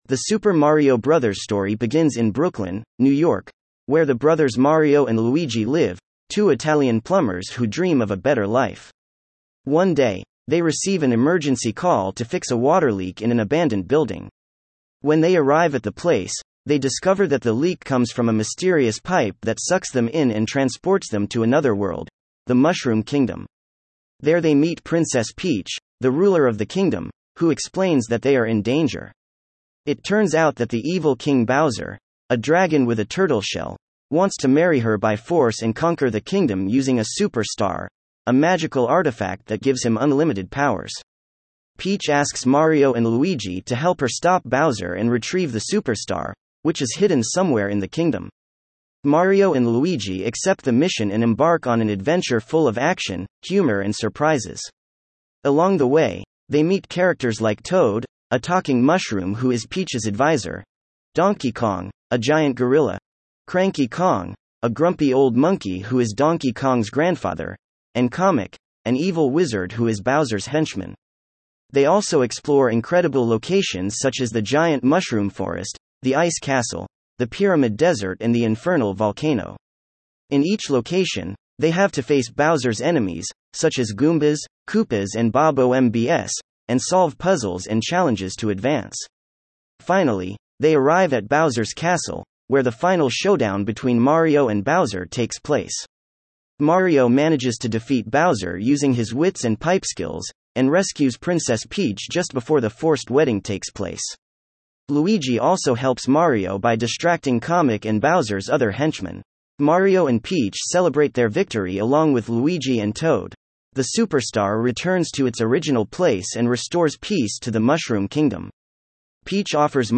Here’s the audio story!